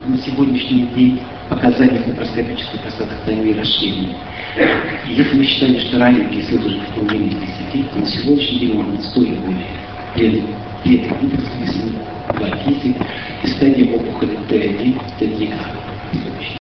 5 Российская Школа по эндоскопической и открытой урологии, 8-10 декабря 2004 года.
Лекция: ЛАПАРОСКОПИЯ В УРОЛОГИИ СЕГОДНЯ.